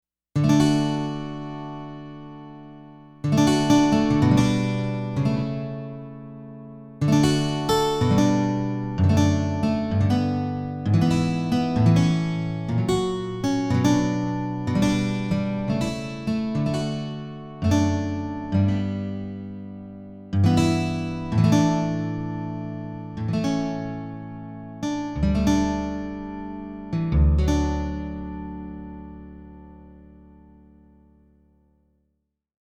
So Good! JUNO-Di Patch Demo